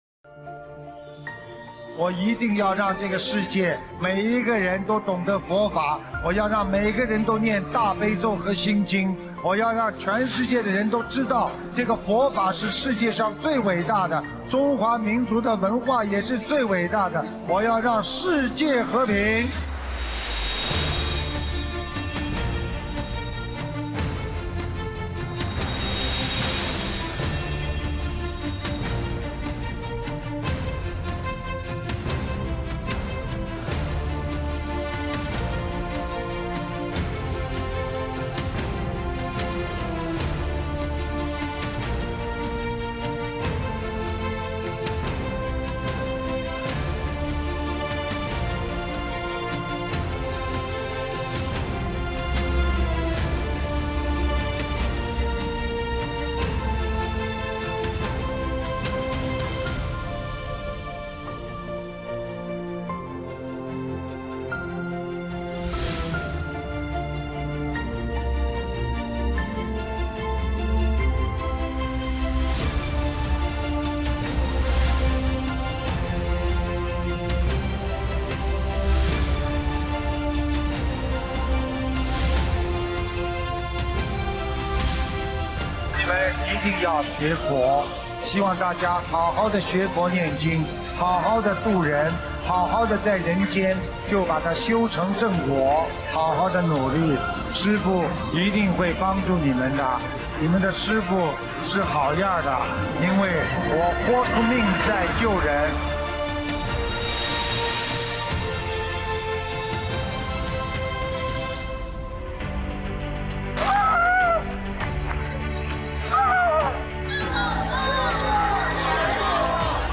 视频：24.佛學會訪談【訪談分享】07 - 新闻报道 心灵净土